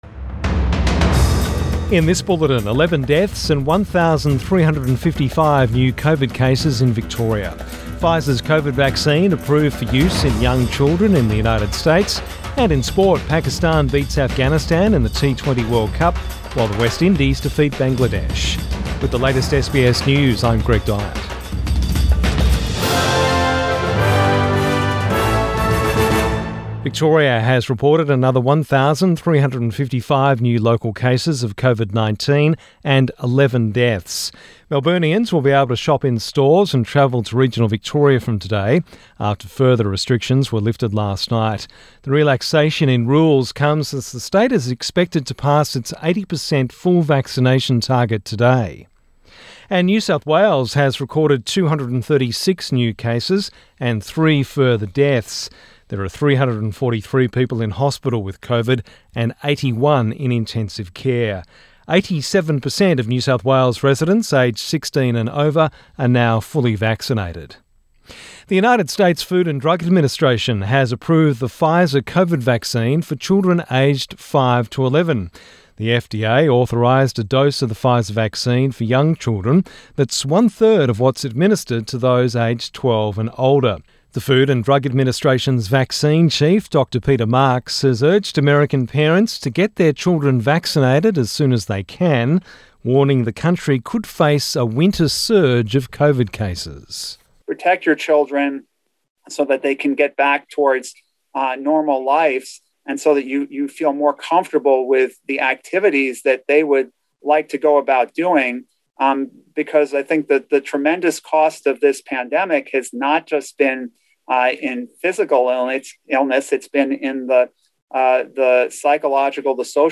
Midday bulletin 30 October 2021